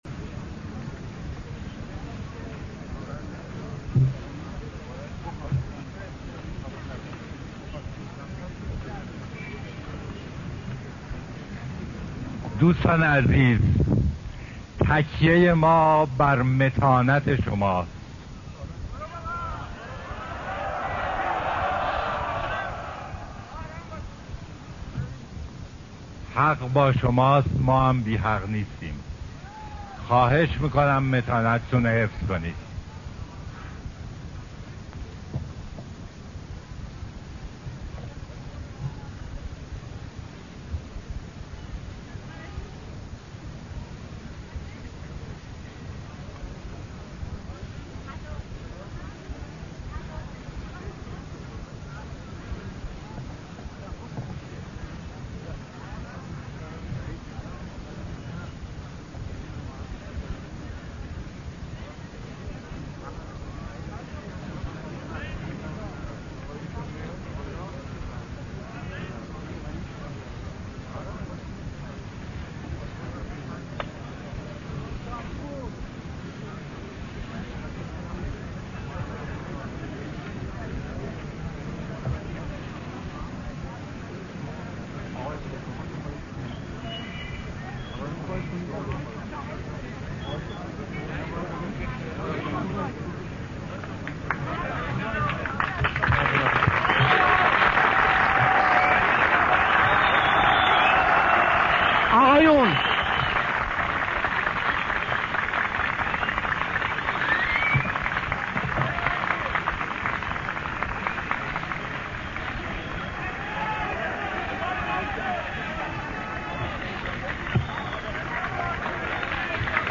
بمناسبت سالگرد تولد سیاوش کسرائی با گام های جنبش سبز شعرخوانی او در شب های شعر انستیتو گوته را بشنوید